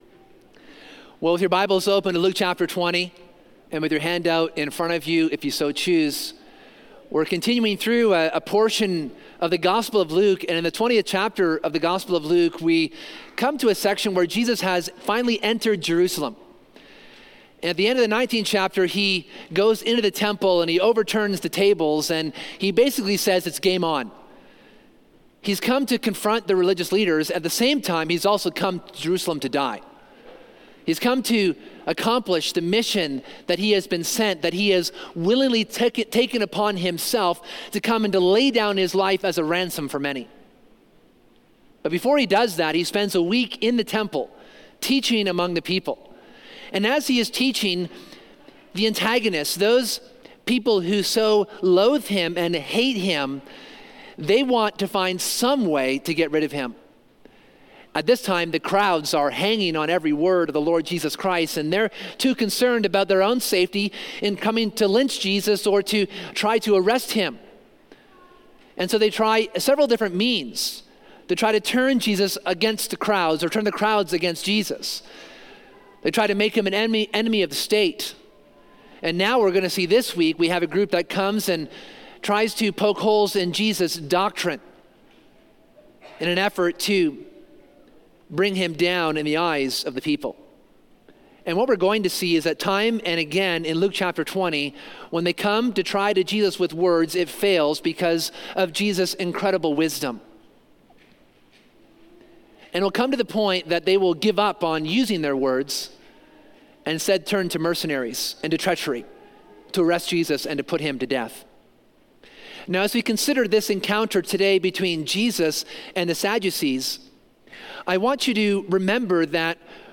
This sermon considers the Sadducees’ questioning of the resurrection in an attempt to make Jesus look foolish.